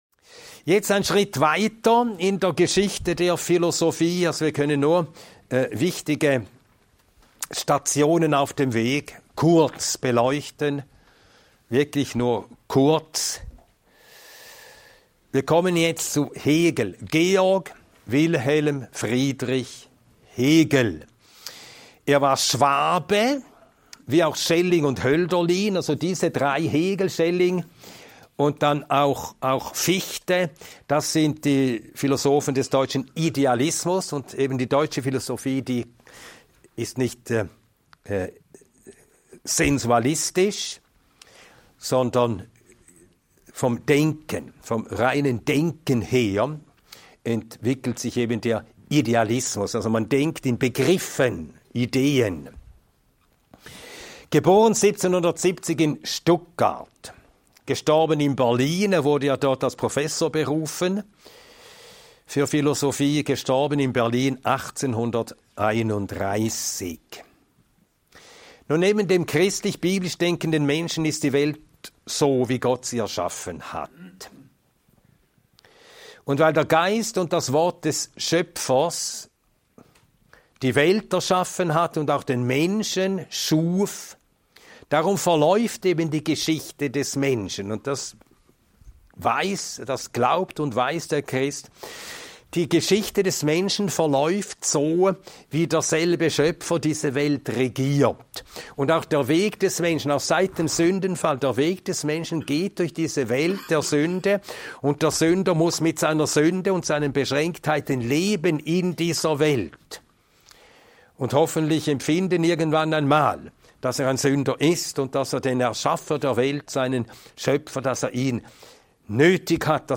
Dieser Vortrag zeigt auf, wie Hegel die biblischen Grundgedanken übernimmt, sie aber in ein rein philosophisches System überführt.